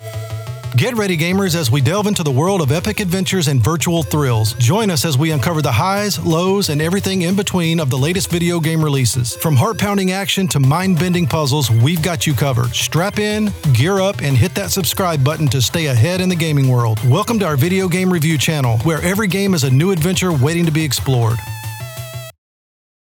YouTube Video Game Channel Intro
YouTube Video Game Channel Intro_1.29.25.mp3